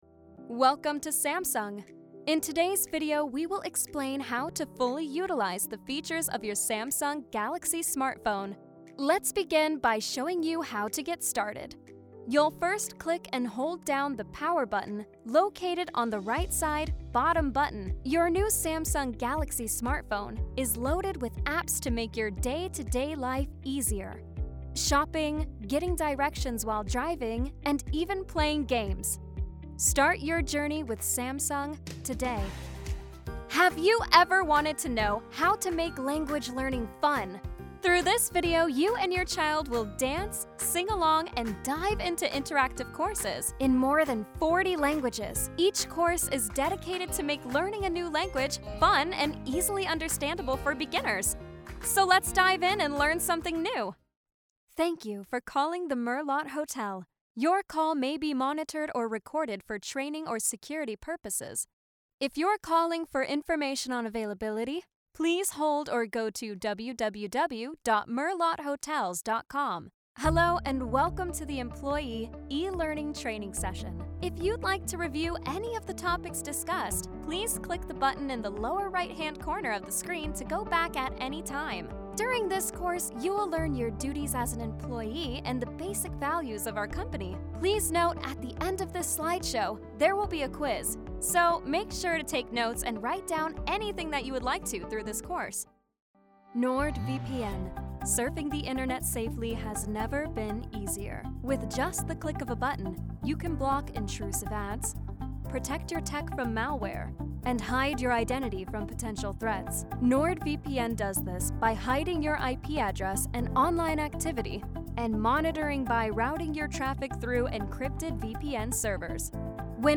Commercieel, Jong, Natuurlijk, Opvallend, Veelzijdig
Explainer